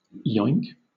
Ääntäminen
Ääntäminen US UK Southern England Tuntematon aksentti: IPA : /jɔɪŋk/ Haettu sana löytyi näillä lähdekielillä: englanti Kieli Käännökset suomi naps Määritelmät Verbi (onomatopoeia) To make an oinking sound .